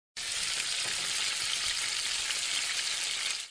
煎东西.mp3